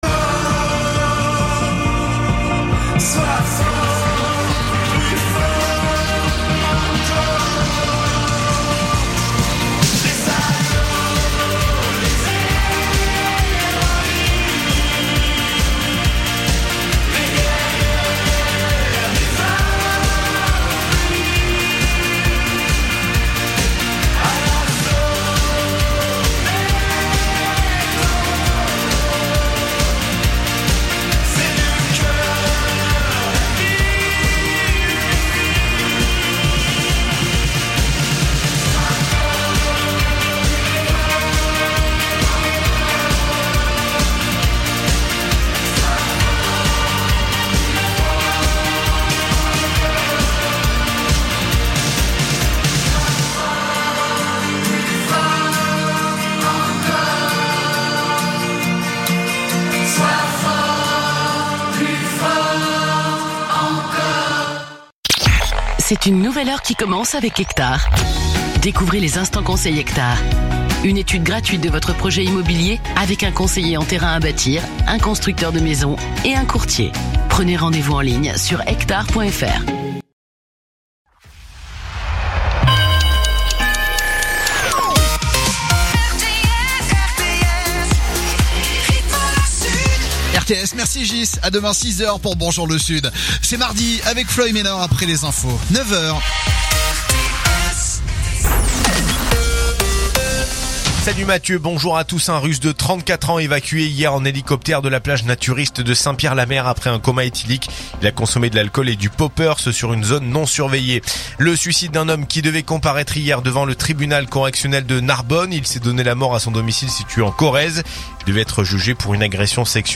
info_narbonne_toulouse_116.mp3